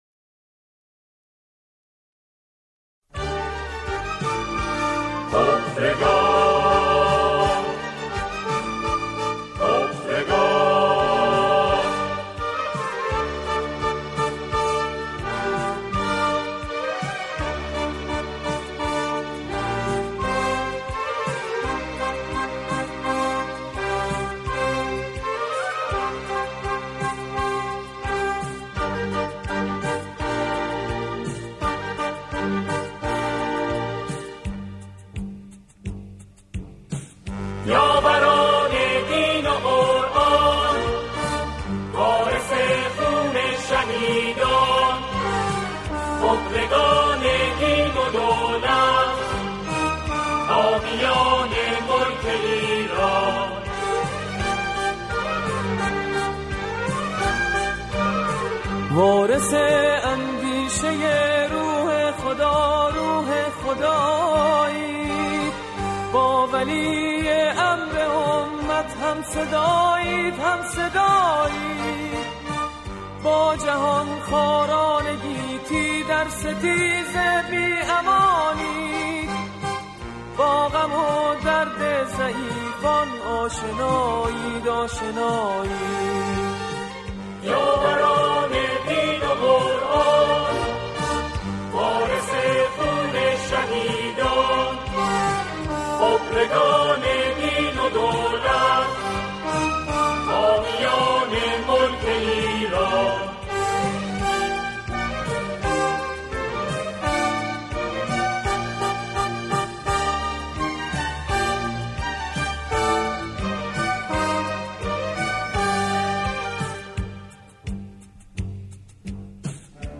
در این قطعه، شعری با موضوع انتخابات همخوانی می‌شود.